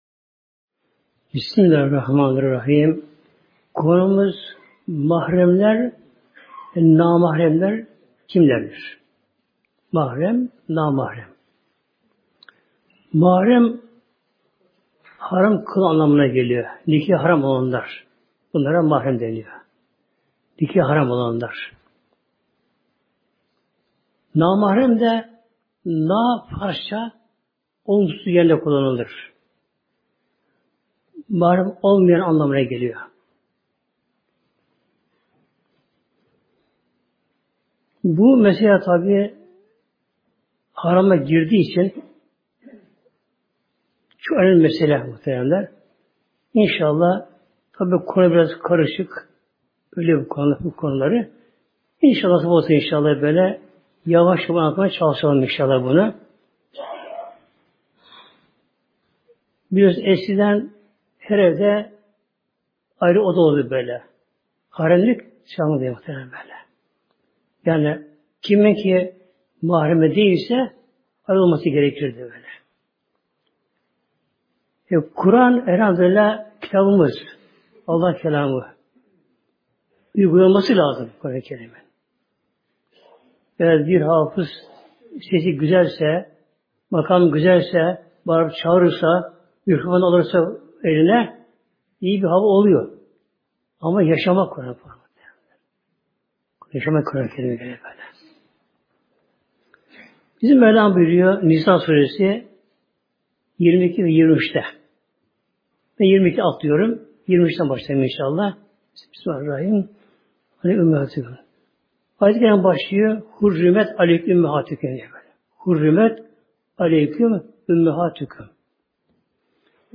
Sohbet